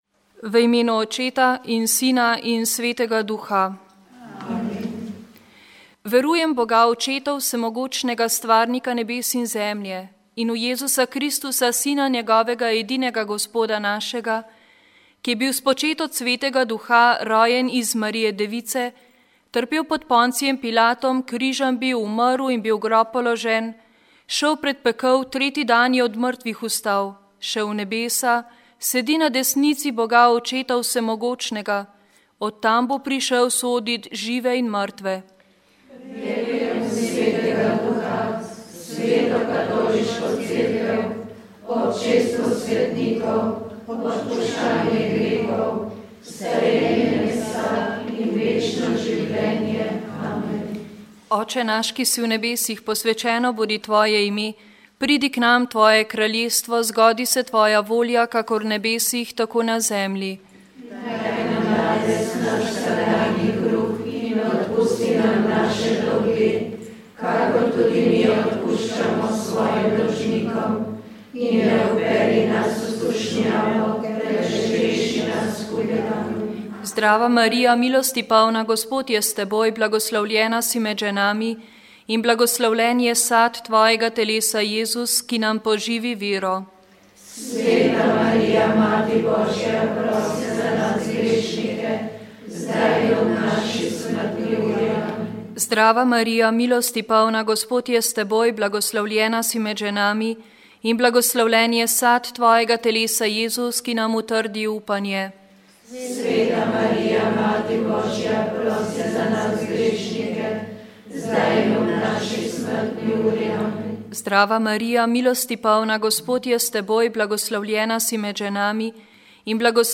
Rožni venec
Molile so redovnice - Sestre svetega Križa.